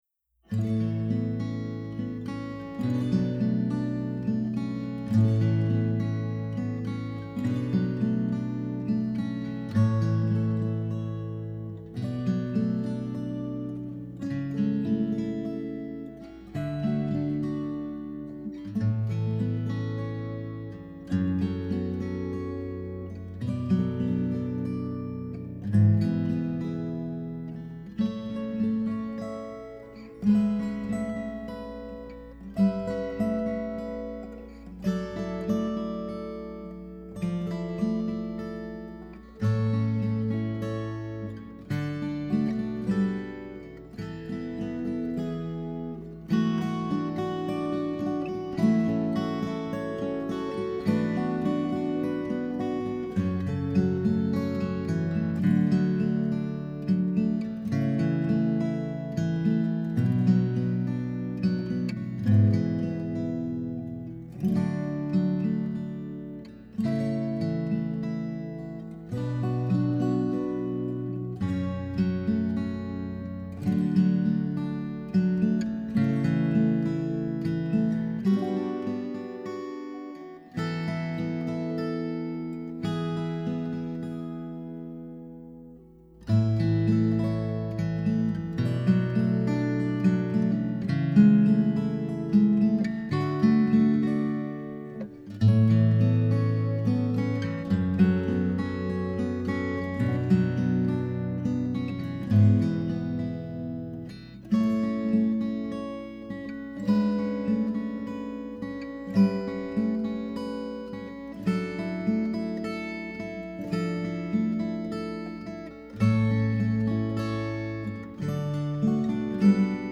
SET YOU FREE – Two guitars
set-you-free-gtr-mix-12.mp3